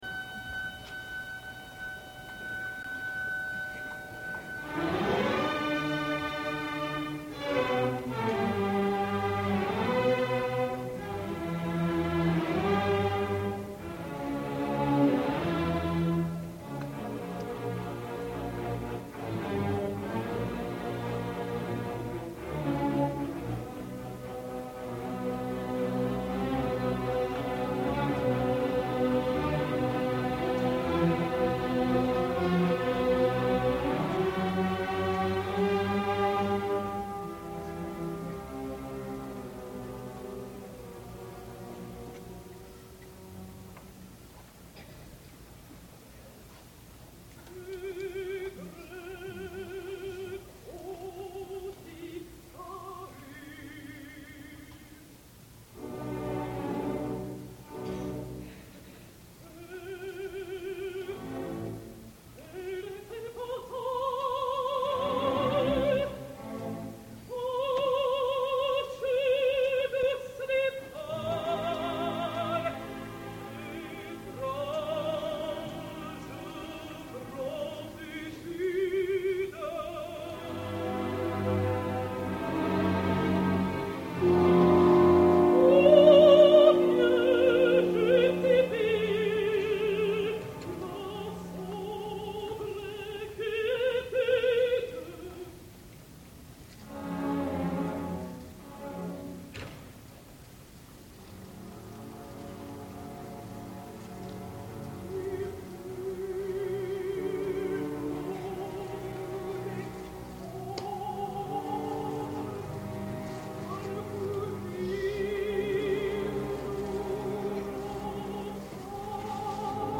Shirley Verrett com a Cassandra
L’escoltem el fragment, en primer lloc per Shirley Verrett, l’extraordinària cantant nordamericana, que va assolir els dos rols (ella estava destinada per se la Cassandra però en les primeres funcions va assumir la Dido, per malaltia de Christa Ludwig), en les primeres representacions d’aquesta òpera al MET, l’any 1973, dirigides per Rafael Kubelik.
Verrett, sempre intensa, musical i dotada d’una gran capacitat dramàtica, ens ofereix una interpretació modèlica.
Sólo he podido oír a Verret, versión plena de emoción y voz.